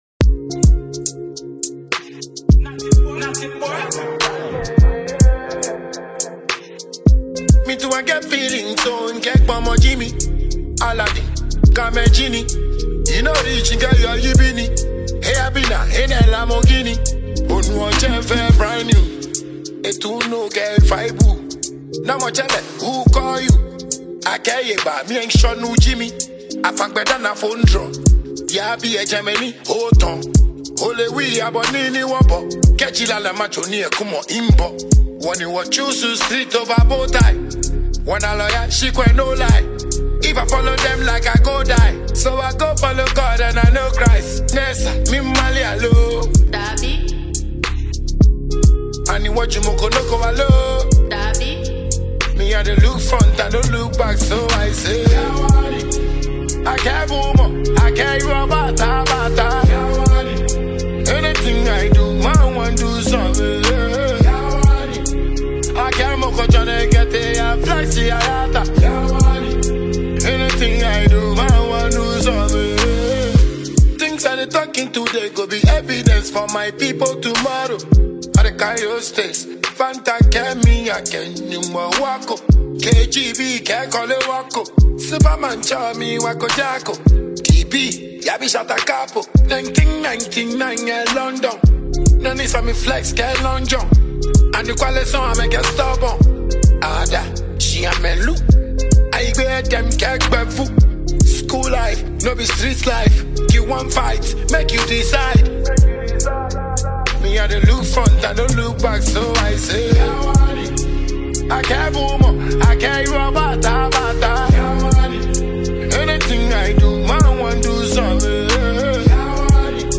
Ghana Music
a Ghanaian dancehall bufalo.